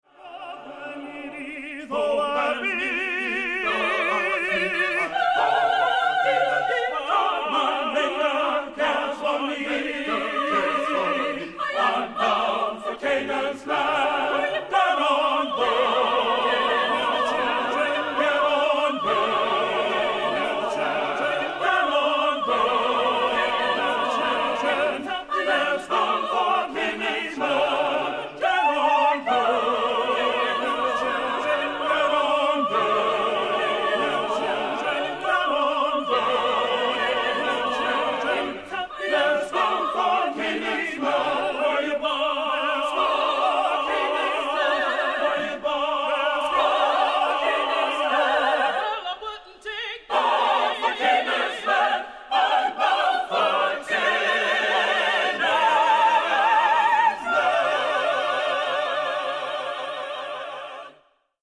Accompaniment:      None, Soprano Solo
Music Category:      Christian